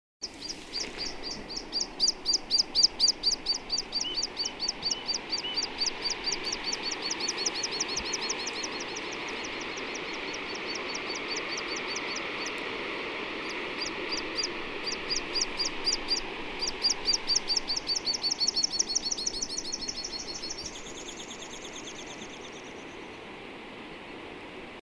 Vuorikirvinen / Water Pipit (Anthus spinoletta)
(9.) Heinäkuu 2005 Kirgisia Laulu. / July 2005, Kyrgyzstan. Song.